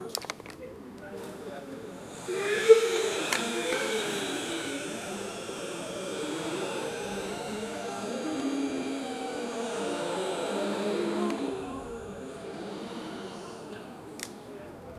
Royalty-free crowd-reactions sound effects
camera-shutter-crowd-gasp-3gsd4u3t.wav